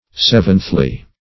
\Sev"enth*ly\